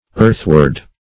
Search Result for " earthward" : The Collaborative International Dictionary of English v.0.48: Earthward \Earth"ward\, Earthwards \Earth"wards\, adv. Toward the earth; -- opposed to heavenward or skyward .
earthward.mp3